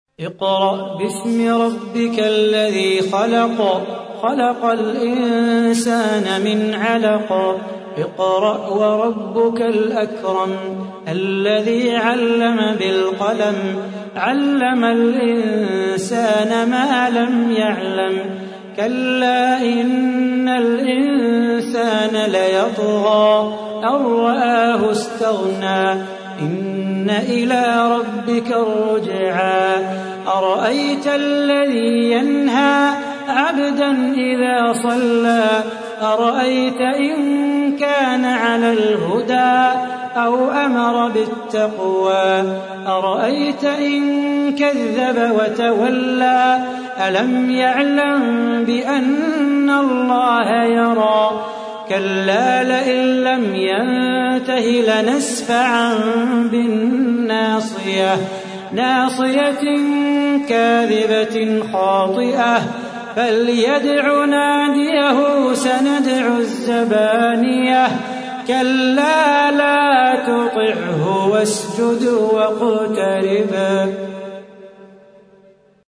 تحميل : 96. سورة العلق / القارئ صلاح بو خاطر / القرآن الكريم / موقع يا حسين